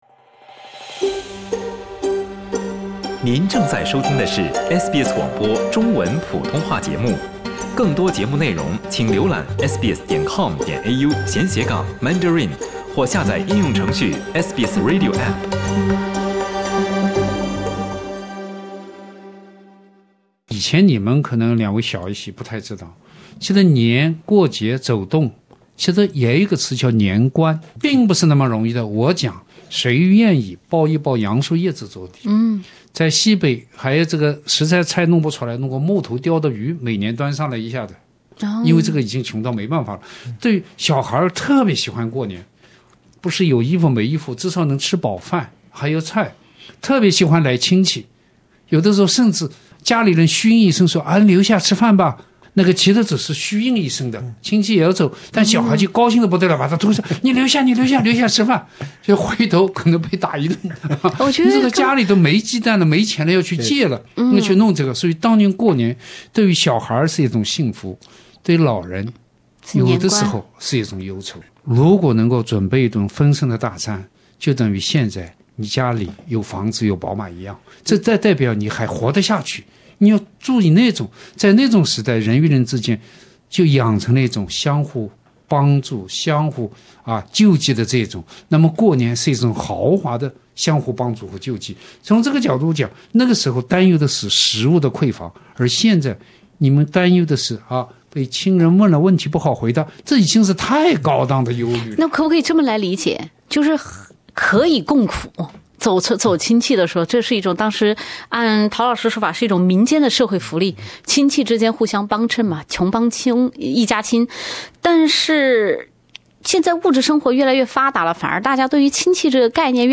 SBS电台《文化苦丁茶》每周五早上澳洲东部时间早上8:15播出，每周日早上8:15重播。